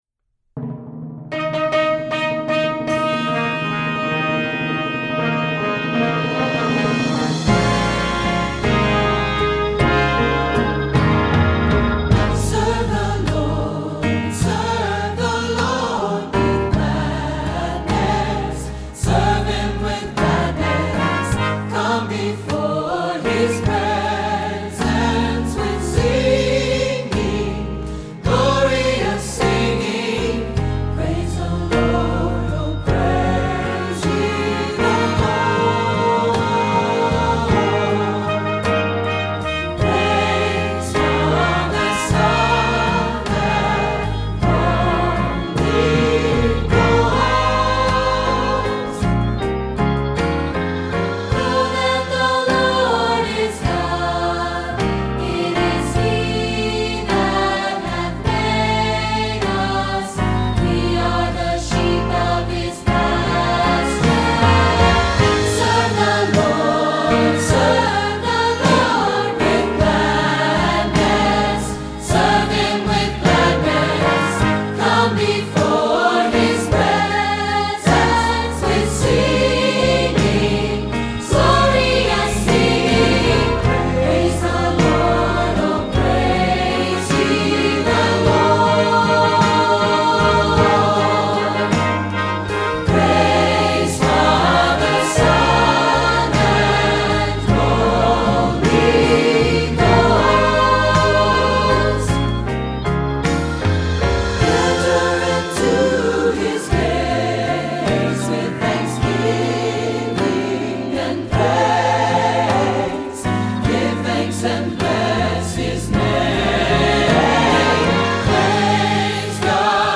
Voicing: Instrumental Parts